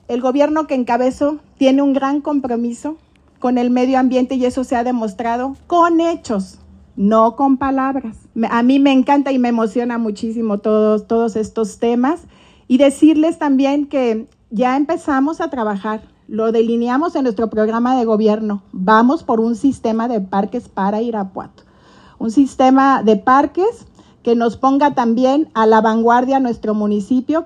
La inauguración del Centro de Educación Ambiental, se dio como parte de los festejos por el 478 aniversario de la fundación de Irapuato.